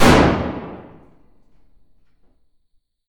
Heavy Weapons And Explosions Sound Effects – Cannon-explosion-sound-3 – Free Music Download For Creators
Heavy_Weapons_And_Explosions_Sound_Effects_-_cannon-explosion-sound-3.mp3